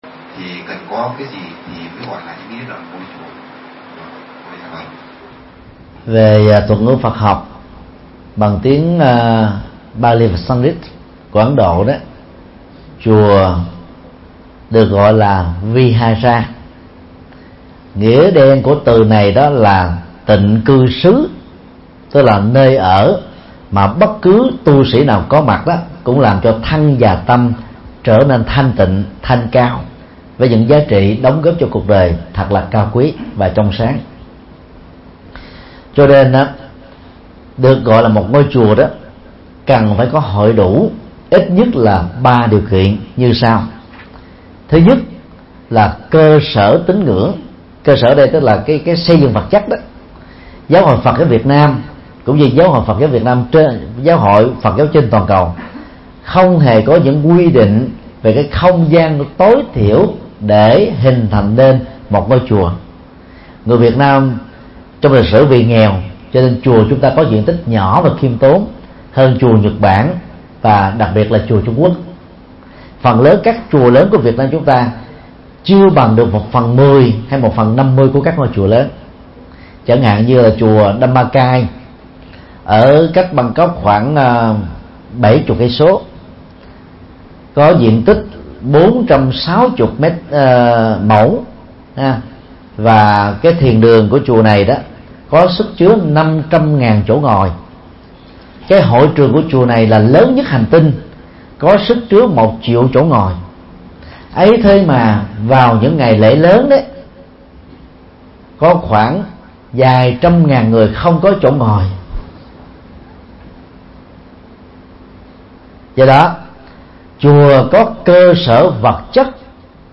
Vấn đáp: Ý nghĩa ngôi chùa